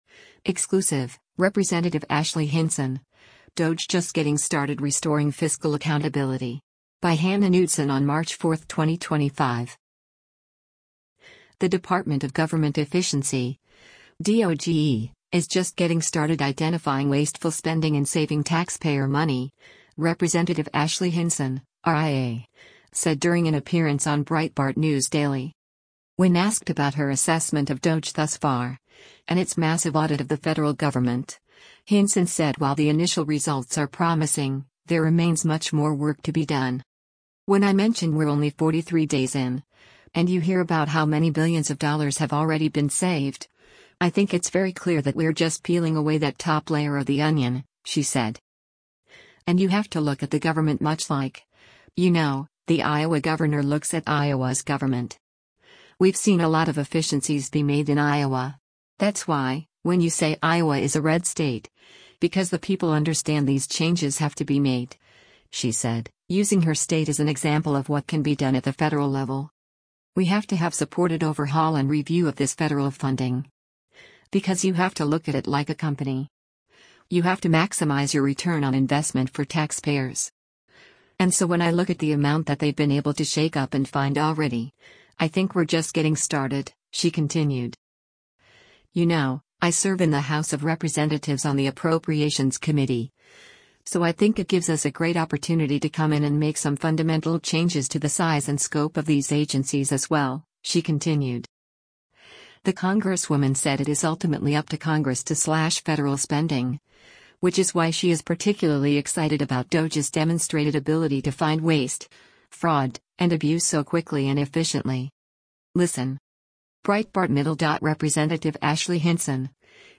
The Department of Government Efficiency (DOGE) is “just getting started” identifying wasteful spending and saving taxpayer money, Rep. Ashley Hinson (R-IA) said during an appearance on Breitbart News Daily.
Breitbart News Daily airs on SiriusXM Patriot 125 from 6:00 a.m. to 9:00 a.m. Eastern.